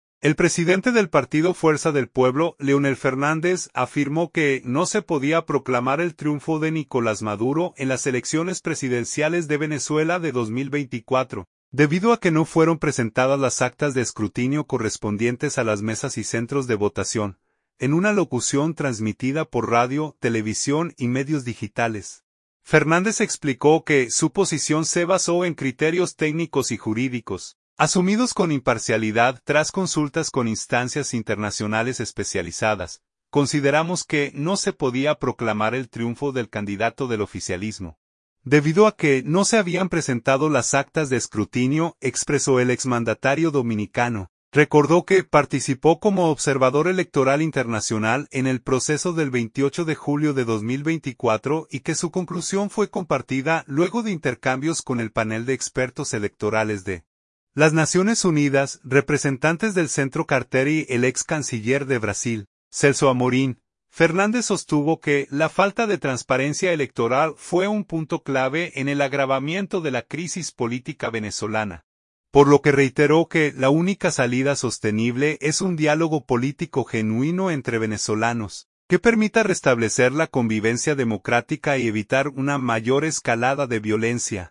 En una alocución transmitida por radio, televisión y medios digitales, Fernández explicó que su posición se basó en criterios técnicos y jurídicos, asumidos con imparcialidad, tras consultas con instancias internacionales especializadas.